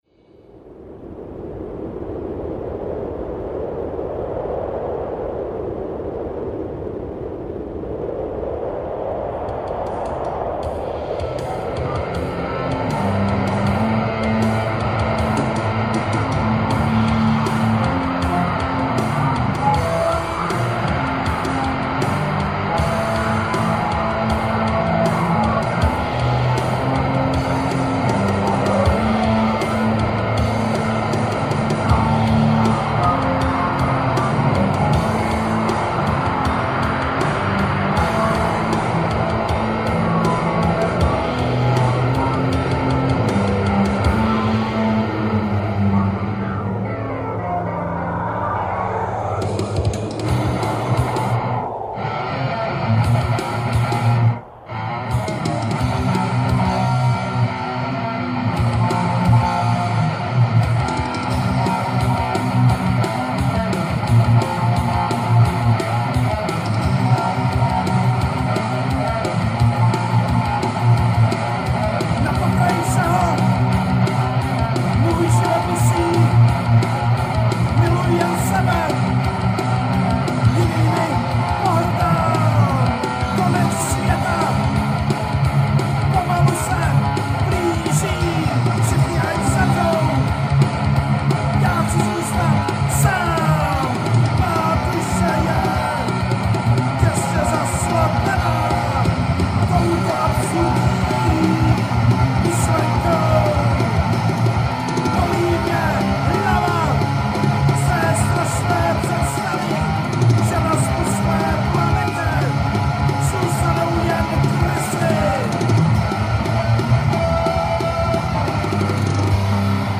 Žánr: Metal/HC
Old Thrash Power Metal